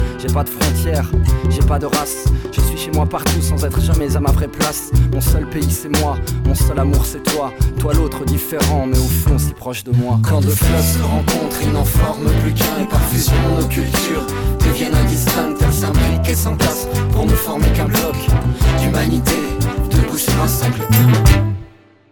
Sonnerie spéciale